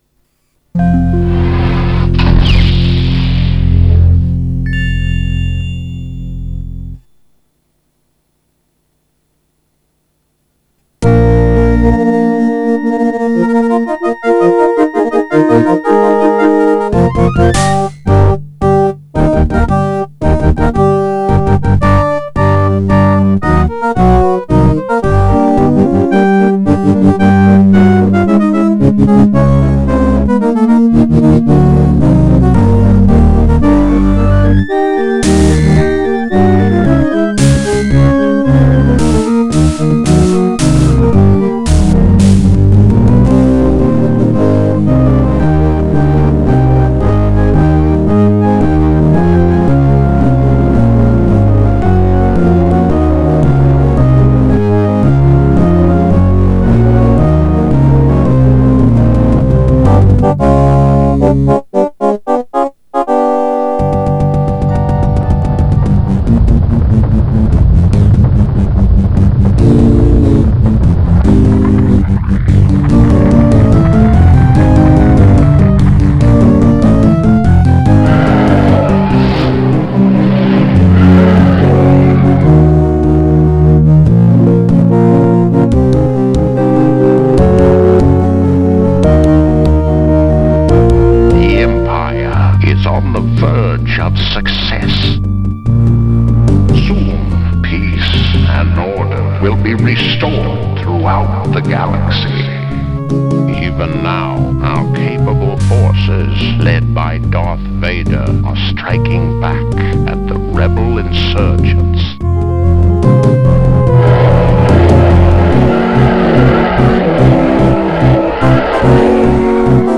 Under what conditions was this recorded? Pentium 200, SB Pro/SB Pro 44 kHz stereo FLAC recording Weighted SnR (Speaker Out): -70 dB (Speaker out on left, Line Out on right)